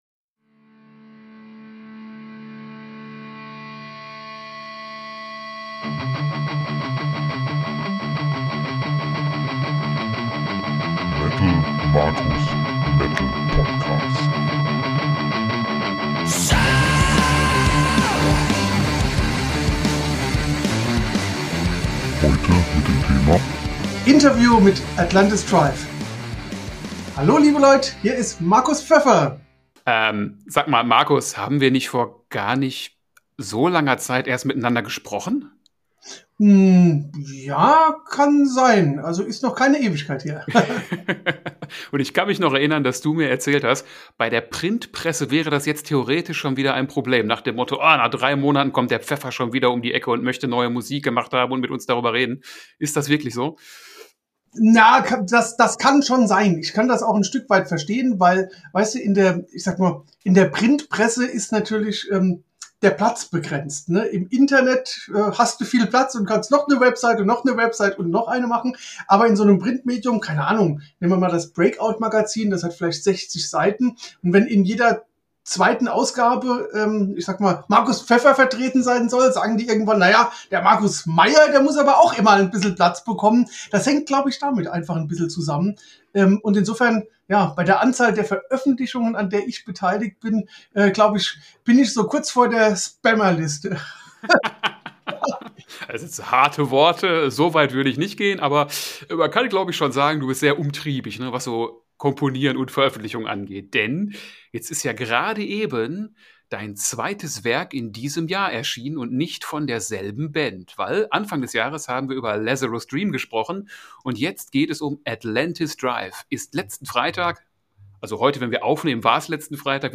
Folge 44 - Interview mit Atlantis Drive